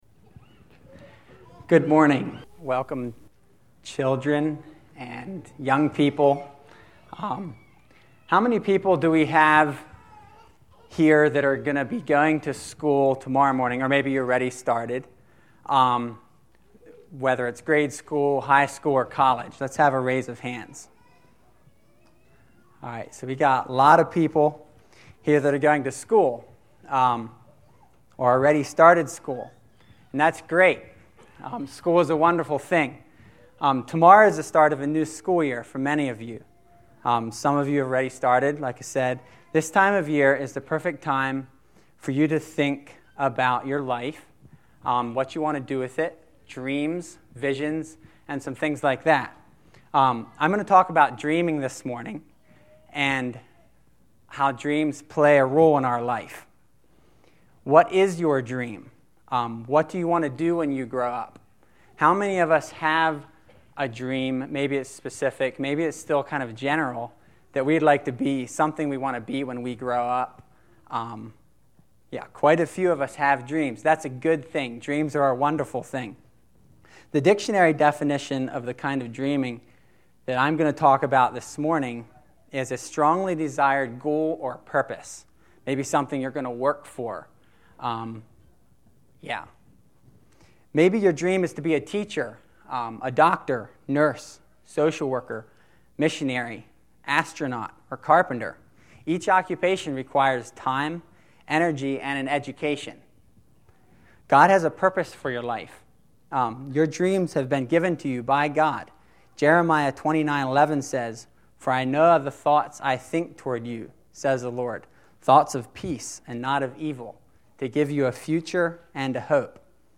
Back To School Service